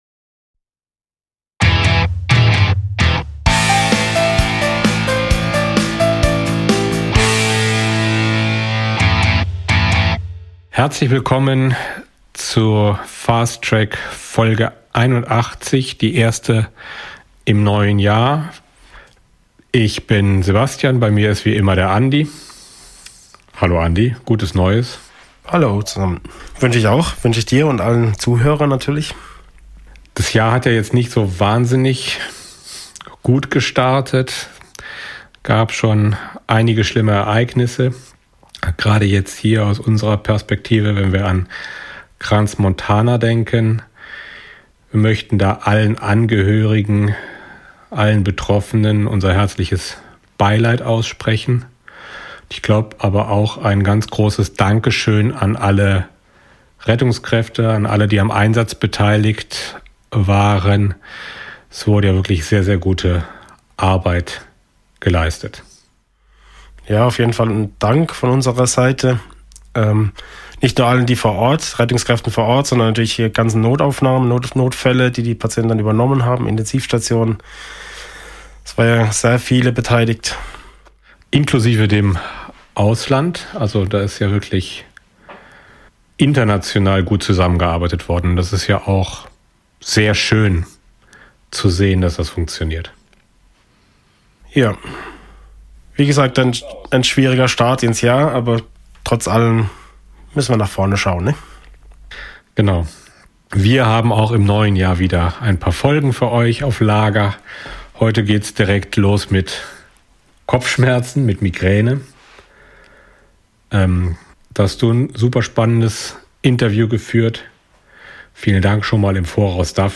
Wir sprechen darüber was man von der Pathophysiologie der Migräne weiss und vor allem welche Möglichkeiten der Schmerztherapie wirkungsvoll sind. Als Andenken an die vielen Opfer des Unglücks in Crans Montana haben wir uns entschlossen in dieser Folge auf einen Eröffungsspruch und einen Witz zu verzichten.